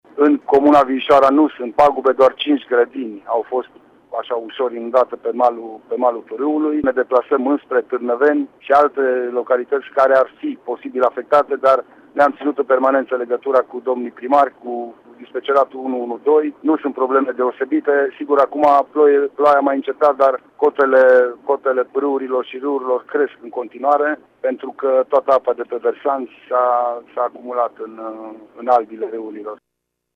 Probleme au fost înregistrate și în localitate Viișoara. 5 grădini situate pe malul pârâului au fost inundate, spune prefectul: